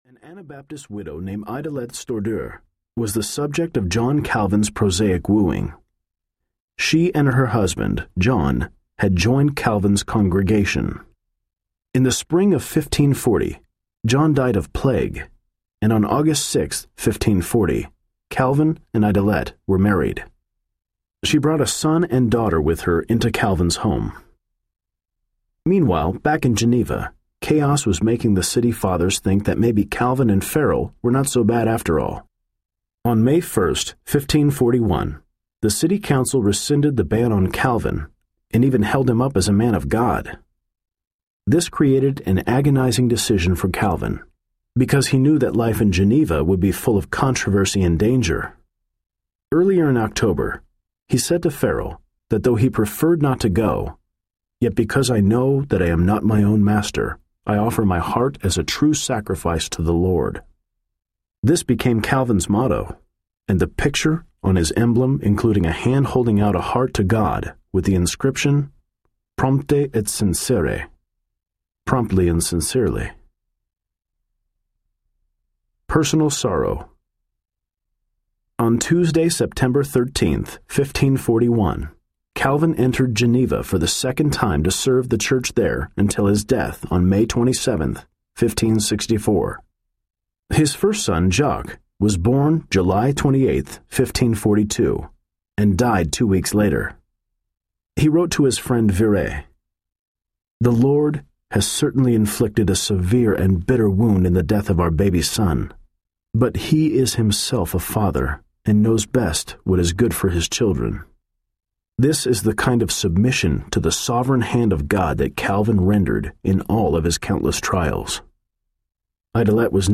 John Calvin and His Passion for the Majesty of God Audiobook
Narrator
1 Hr. – Unabridged